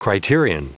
Prononciation du mot criterion en anglais (fichier audio)